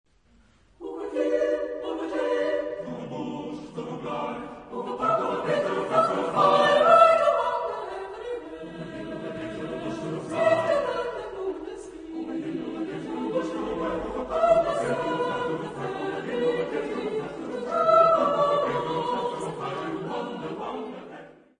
Género/Estilo/Forma: Profano ; Canción ; contemporáneo
Carácter de la pieza : rápido
Tipo de formación coral: SATB  (4 voces Coro mixto )
Tonalidad : sol mayor
for SATB unaccompanied